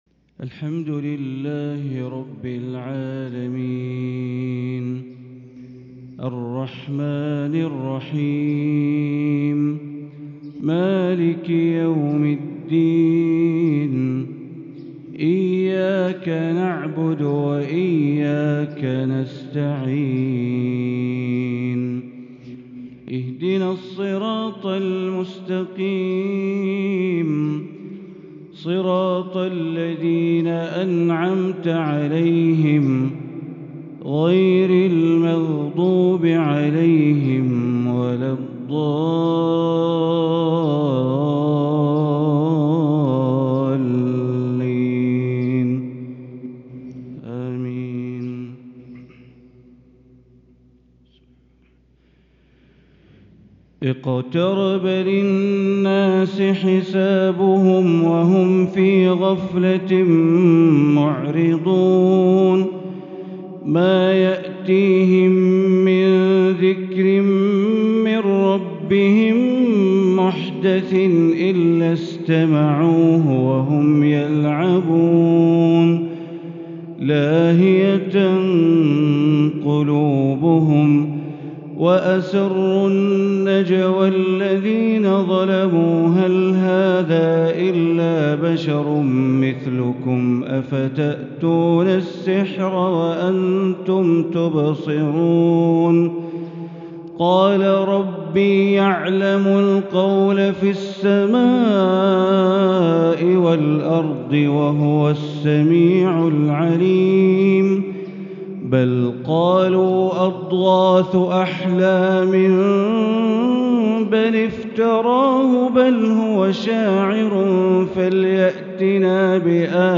فجر الاثنين 9-2-1444هـ فواتح سورة الأنبياء | Fajr prayer from Surah al-Anbiya 5-9-2022 > 1444 🕋 > الفروض - تلاوات الحرمين